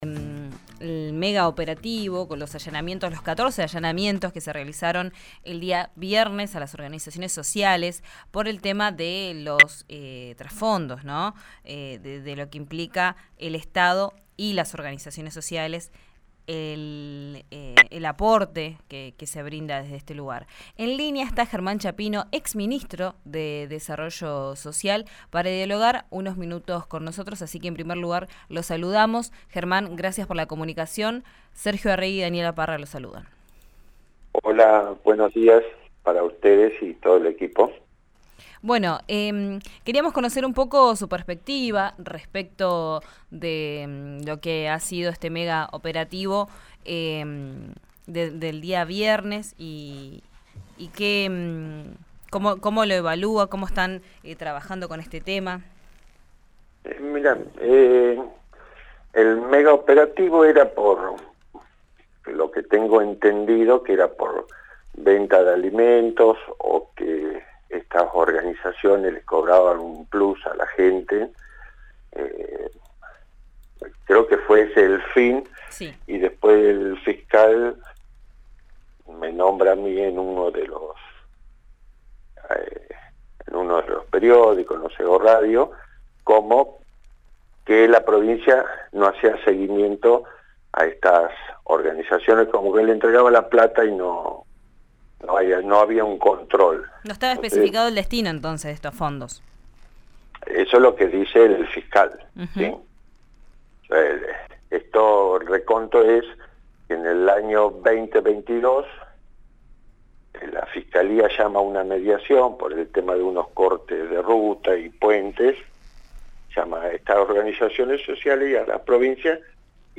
En diálogo con RÍO NEGRO RADIO, el exministro reveló que la cooperativa Viento Sur entregaba un listado de beneficiarios a los cuales les pagaba un subsidio.